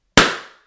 Balloon Pop One
Balloon Bang Pop sound effect free sound royalty free Tiktok Trends